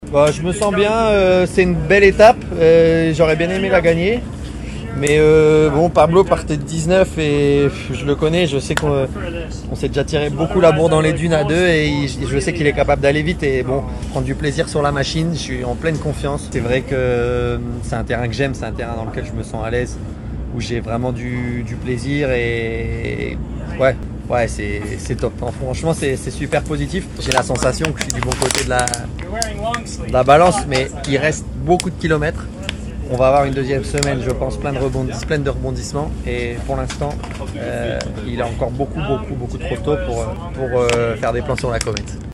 Et il continue sa remontée dans cette 46° édition du DAKAR .HP ILLUSTRATION Je me sens bien ” affirmait il hier à l’arrivée de la 5° étape